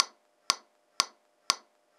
Metrónomo 4x4 a 120 bpm